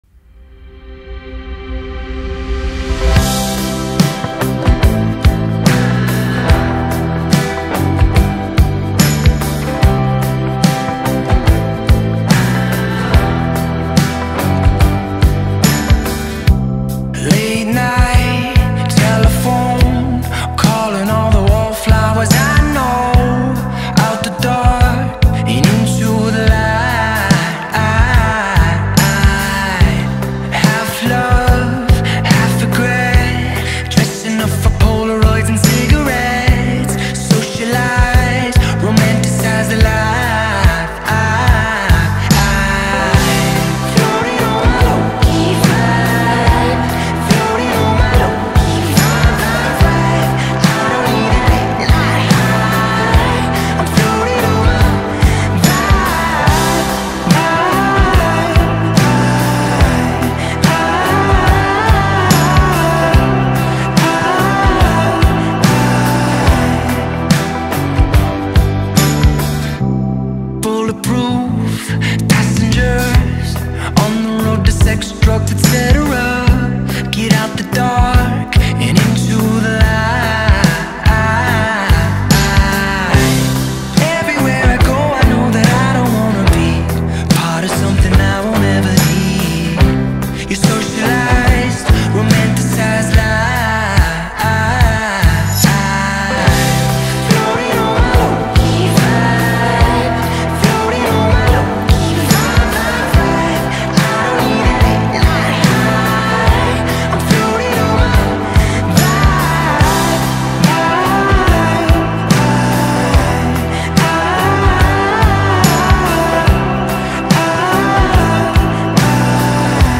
یک گروه پاپ – راک سه نفره